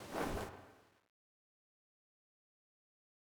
Bag open 3.wav